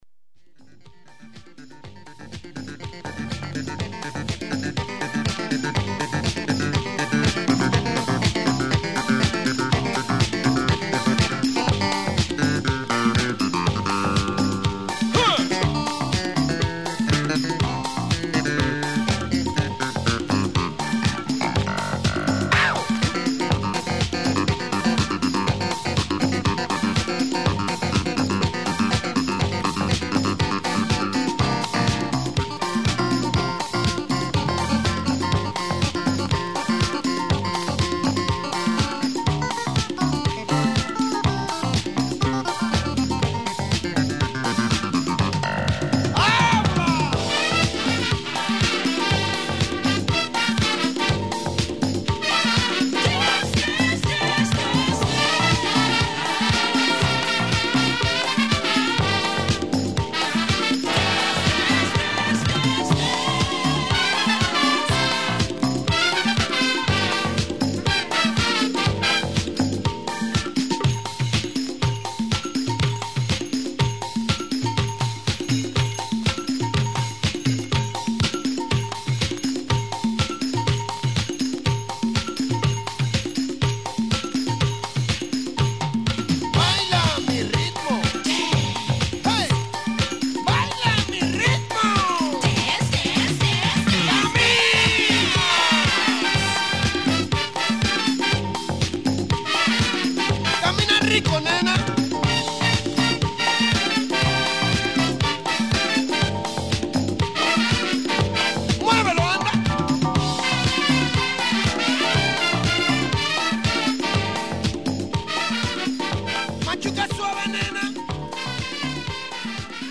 キューバ・ジャズの代表的バンドのディスコ・ファンク!!!